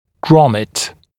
[ˈgrɔmɪt][ˈгромит]трубка для вентиляции среднего уха